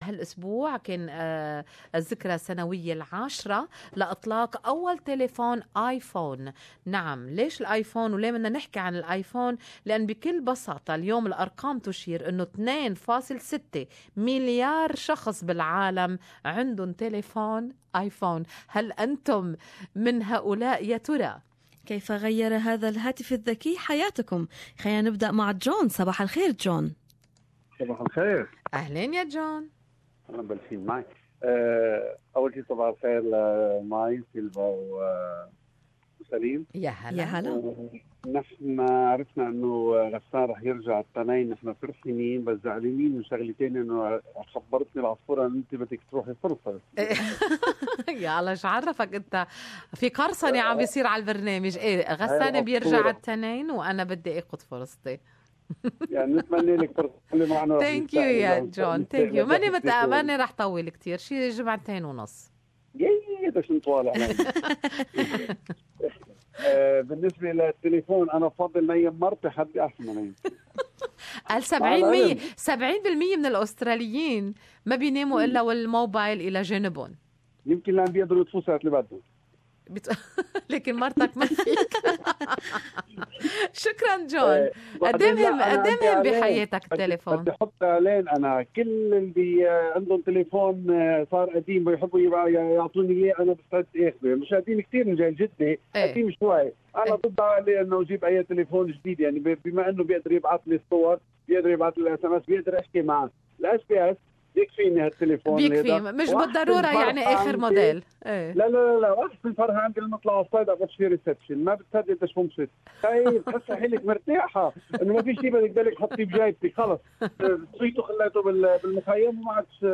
The Good Morning Australia program listeners share their insights.